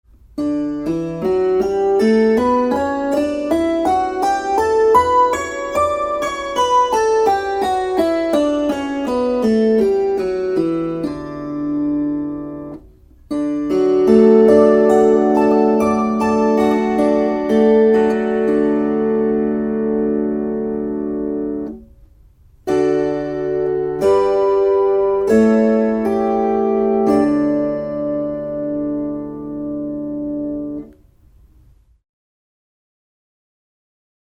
Kuuntele D-duuri. fis cis Opettele duurit C G A E F B Es As mollit a e h fis cis d g c f Tästä pääset harjoittelun etusivulle.
dduuri.mp3